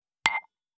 278,食器をぶつける,ガラスをあてる,皿が当たる音,皿の音,台所音,皿を重ねる,カチャ,
コップ